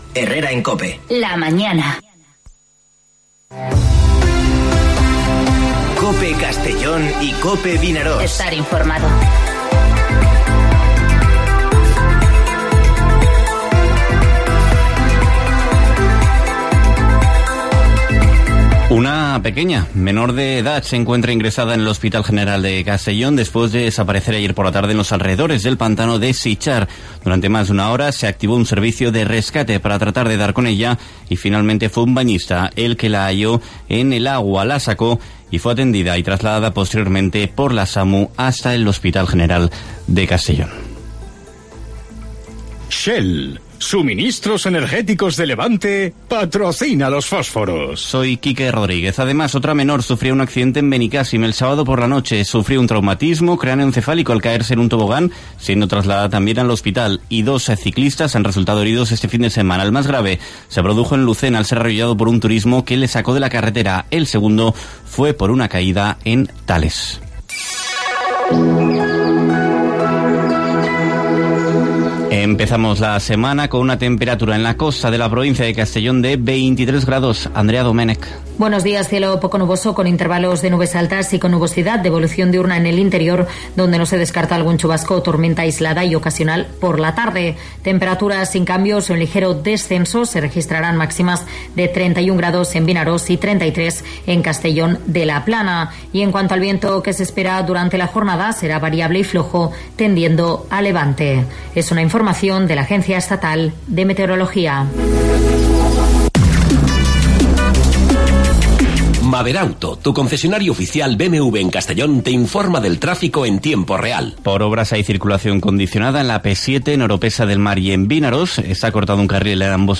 Informativo Herrera en COPE en la provincia de Castellón (10/08/2020)